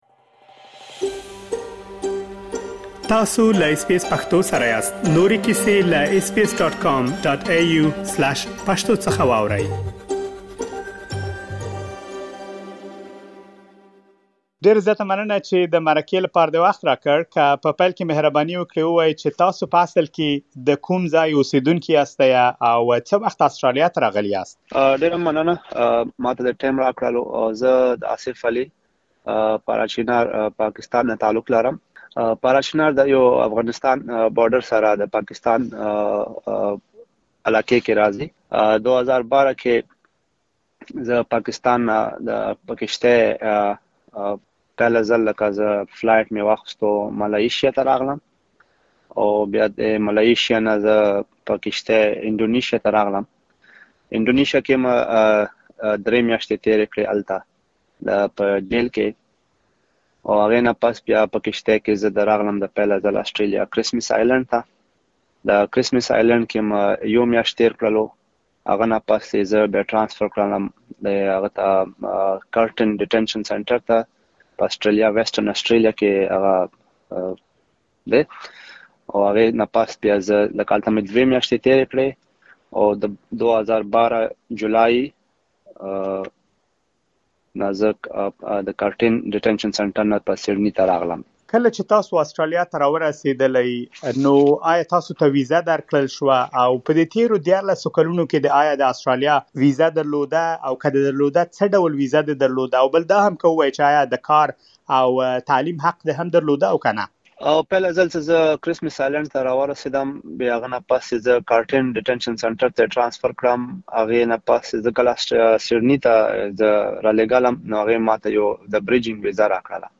اس بي اس پښتو له هغه سره مرکه ترسره کړې، تاسو کولی شئ لا ډېر معلومات په ترسره شوې مرکې کې واورئ.